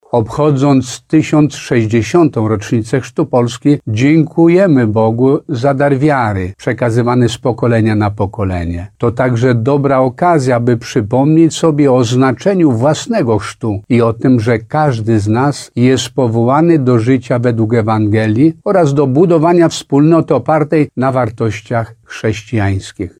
Z tej okazji słowo do wiernych skierował Przewodniczący Episkopatu Polski abp Tadeusz Wojda.